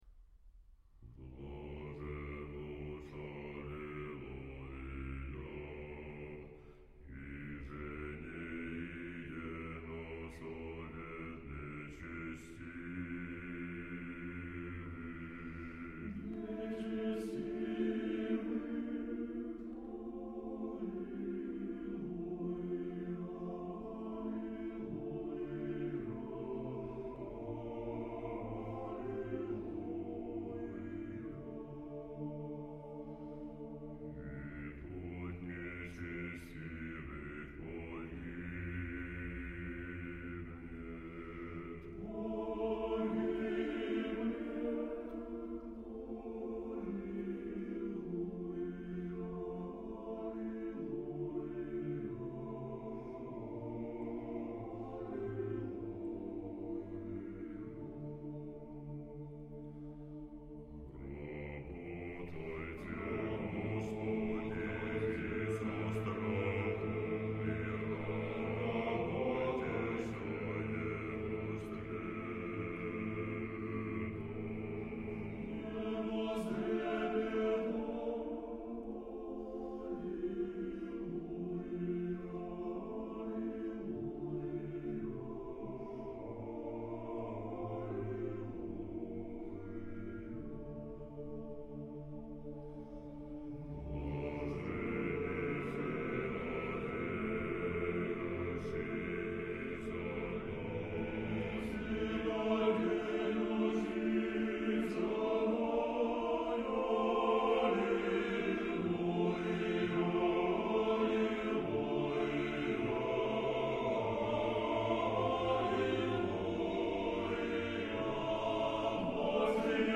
А тут Профундо.То есть басы еще на октаву ниже поют.
Ортокс хор.Басы Профундо.